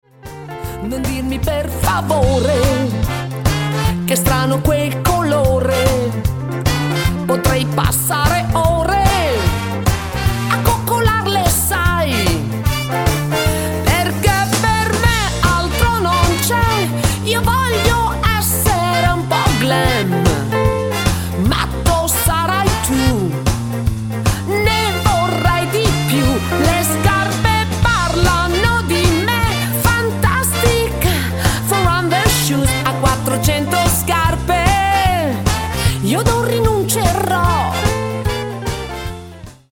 BOOGIE  (3.12)